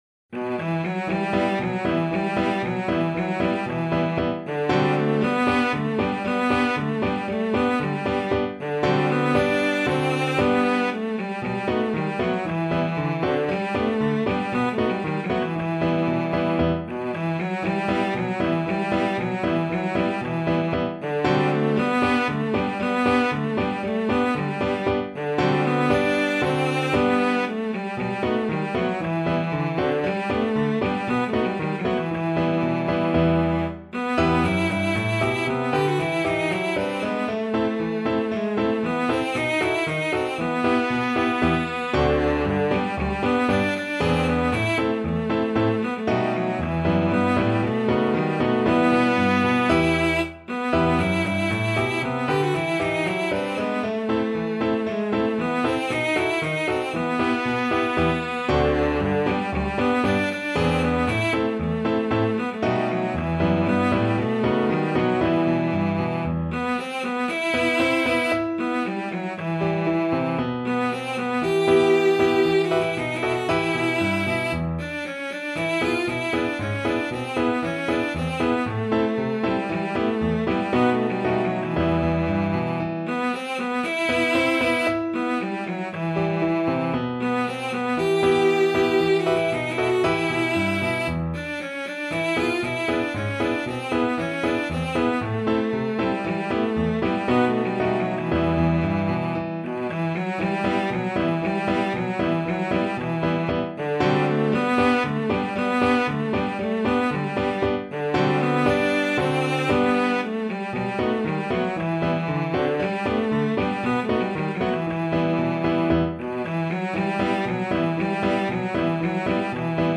Traditional Music of unknown author.
2/4 (View more 2/4 Music)
Allegro =c.116 (View more music marked Allegro)
B3-G5